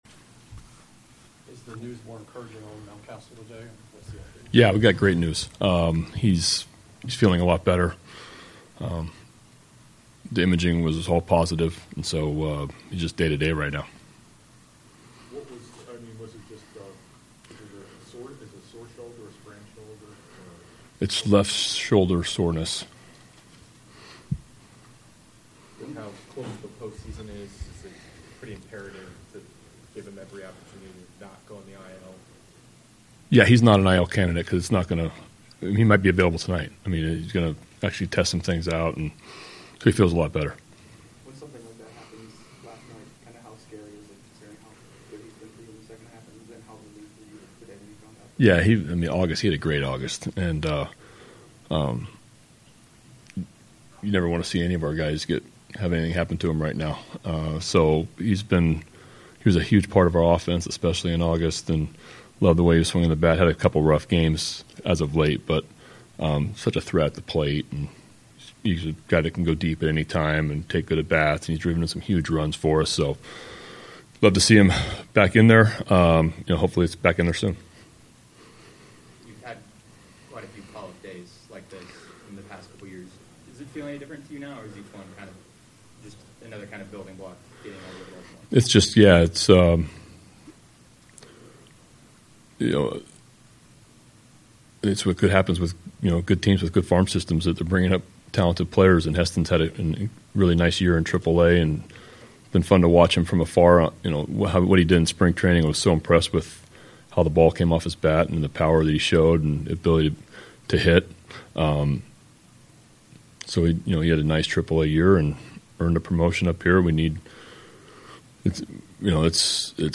Brandon Hyde meets with media prior to big series opener against Tampa Bay
Locker Room Sound